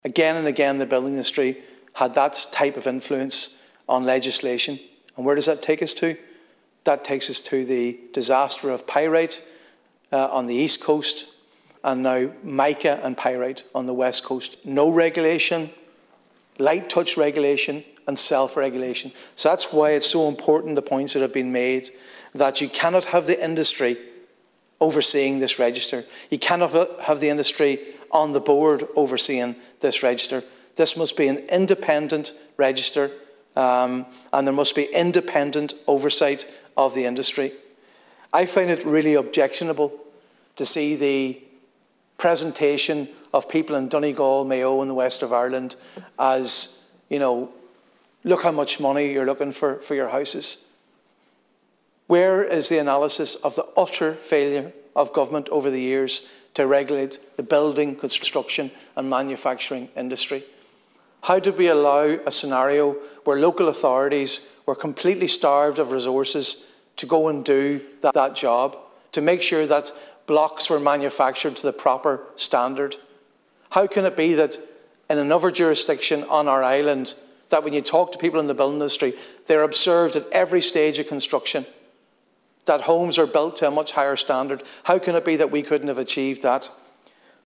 Deputy Padraig MacLochlainn was speaking during a debate on the proposed Regulation of Providers of Building Works Bill.